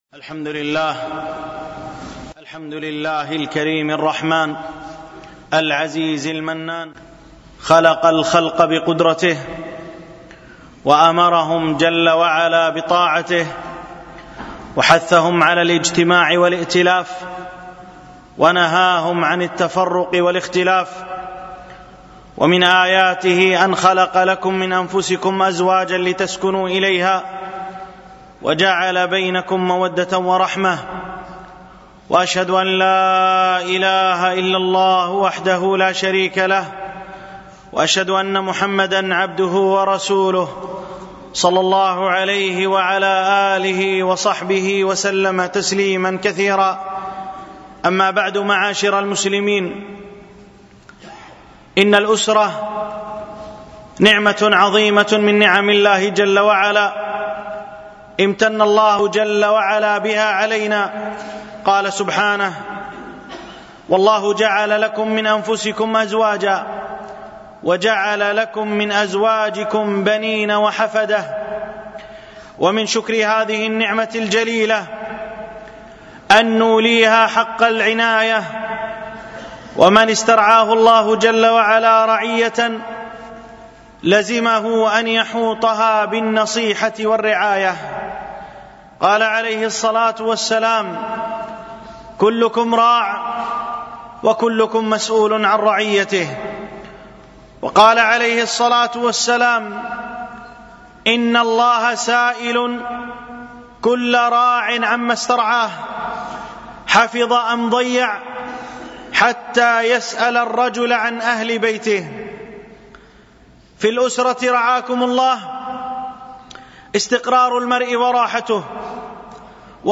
تربية الأبناء ولقاء الأسرة الألبوم: دروس مسجد عائشة (برعاية مركز رياض الصالحين ـ بدبي) المدة